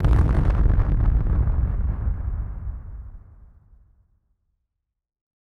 BF_DrumBombC-07.wav